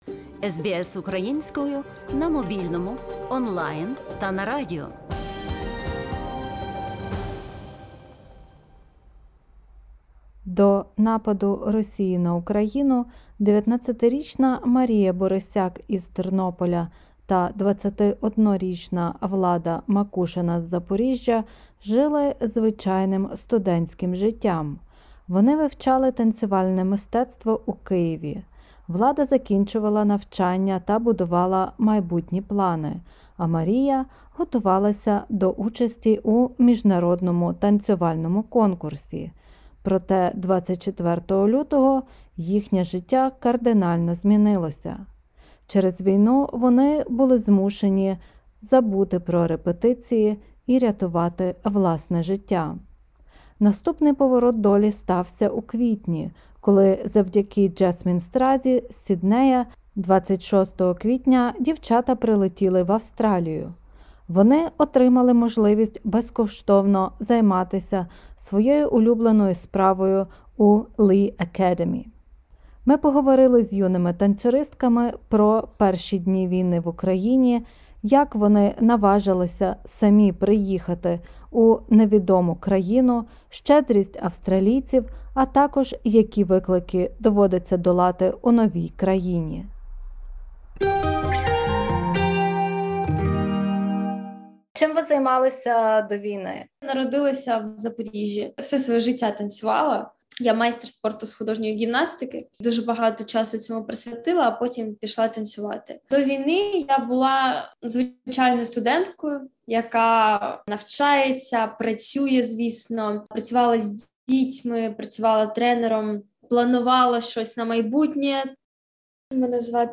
Розмова з київськими студентками про перші дні війни в Україні, як на прийняття рішення самим їхати в Австралію вони мали кілька годин, щедрість австралійців, а також, які виклики доводиться долати в новій країні.